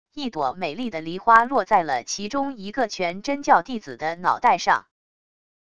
一朵美丽的梨花落在了其中一个全真教弟子的脑袋上wav音频